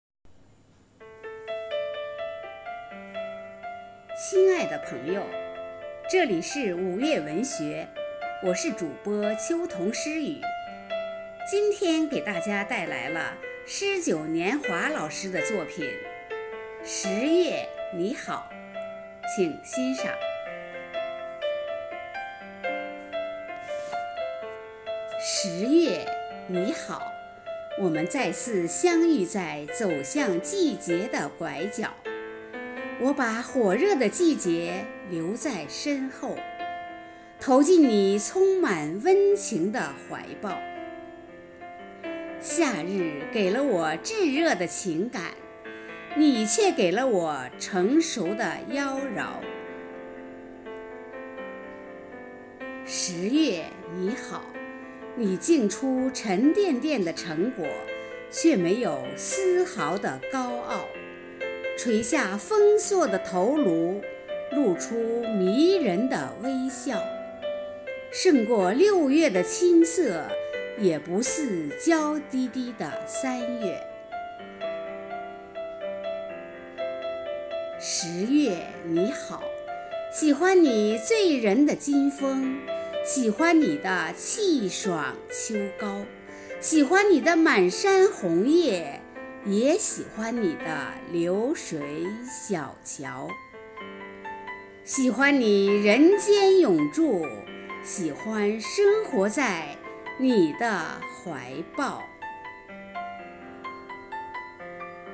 喜欢并热爱聆听美声诵读美文，希望用声音解读诗意诠释生活的美好，用真挚的情感吐纳心声、用美好的心灵感悟人生。